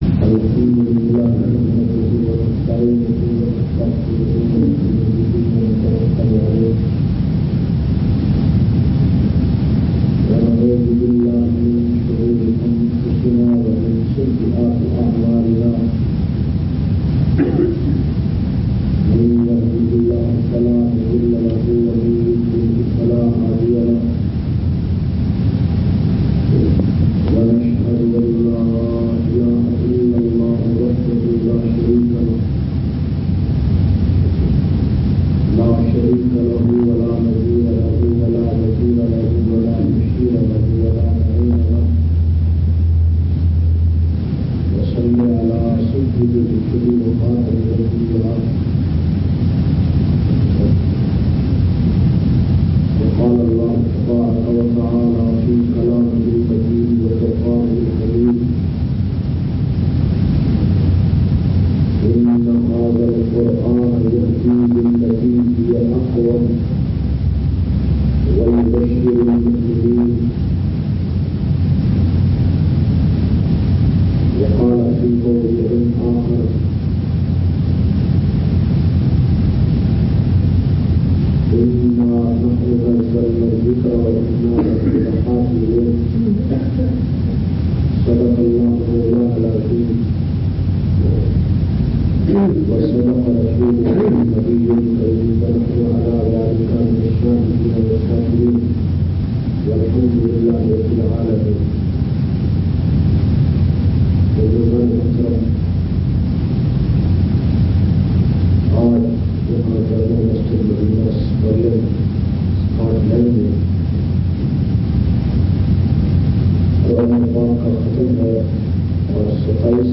415- Hamly k Baad khitab Madina Masjid Stirling Scotland.mp3